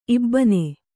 ♪ ibbane